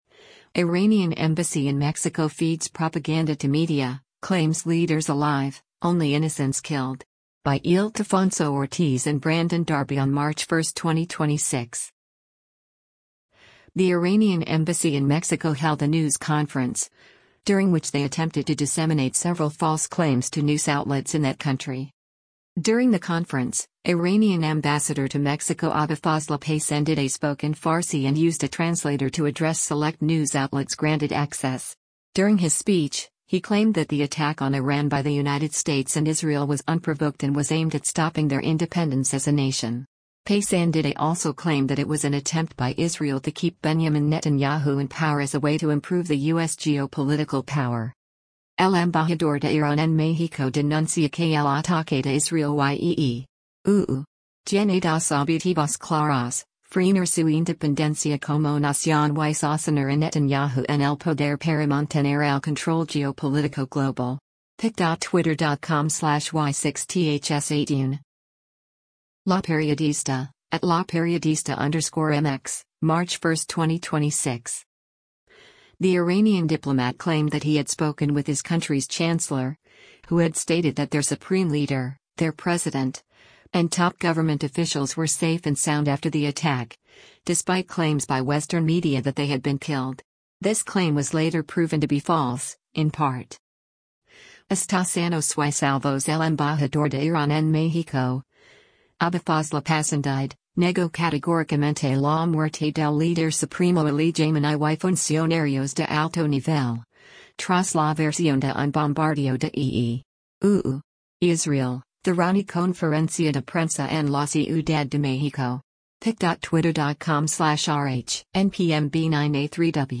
The Iranian Embassy in Mexico held a news conference, during which they attempted to disseminate several false claims to news outlets in that country.
During the conference, Iranian Ambassador to Mexico Abolfazl Pasandideh spoke in Farsi and used a translator to address select news outlets granted access. During his speech, he claimed that the attack on Iran by the United States and Israel was unprovoked and was aimed at stopping their independence as a nation.